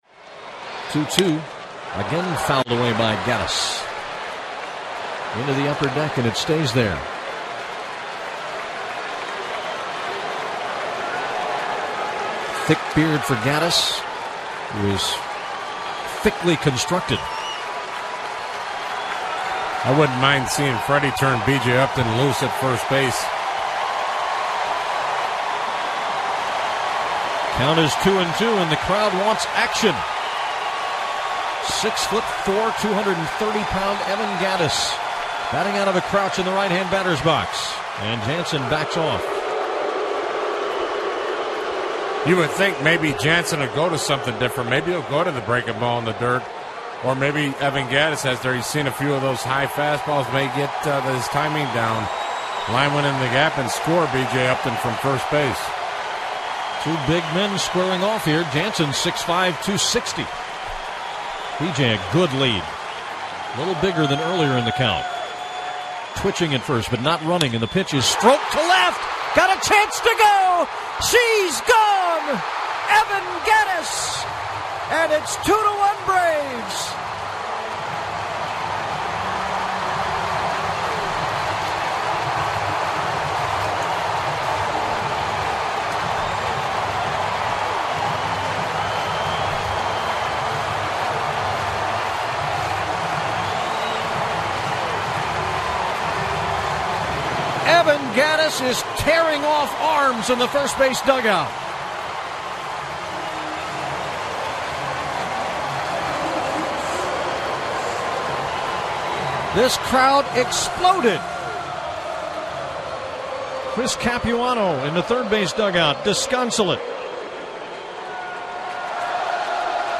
Evan Gattis gives the Braves a 2-1 lead and Andrelton Simmons extends that lead on the next pitch. Jim Powell and Mark Lemke with the call.